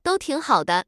tts_result_13.wav